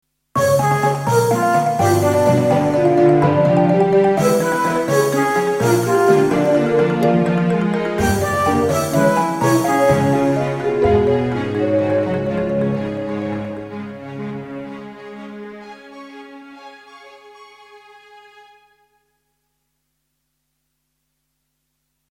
Sterke intromuziek met uitloop